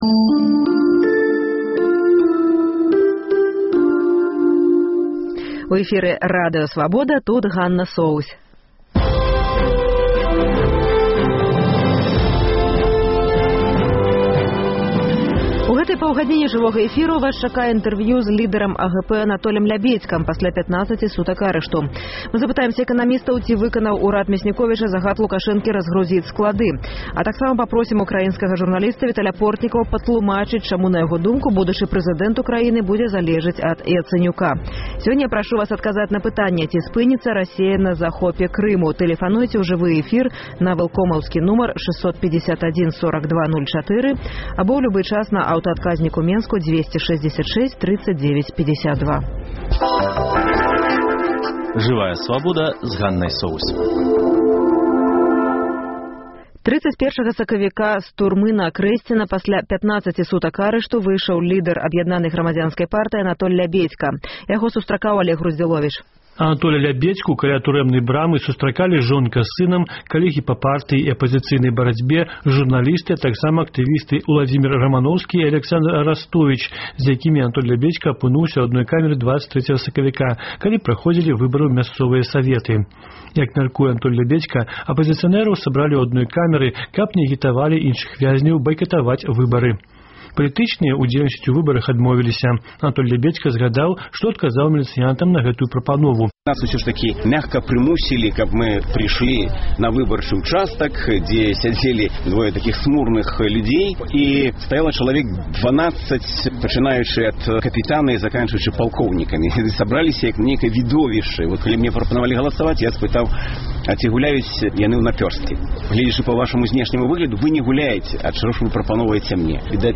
На курсах «Мова ці кава» абмяркоўваюць гарадзкі экстрым. Уключэньне з моўных курсаў Сёньня заканчваецца тэрмін, які Аляксандар Лукашэнка адвёў ураду на разгрузку складоў.
Палітычны партрэт Рэпартаж са станцыі тэхагляду: ажыятаж у сувязі з ростам базавай велічыні Ці была беларусізацыя прымусовай?